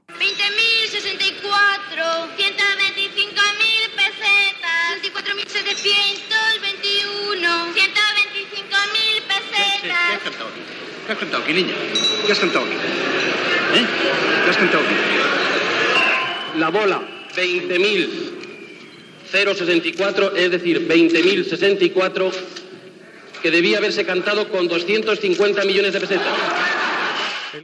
Transmissió del sorteig de la rifa de Nadal amb l'errada del cant del primer premi (20064)
Informatiu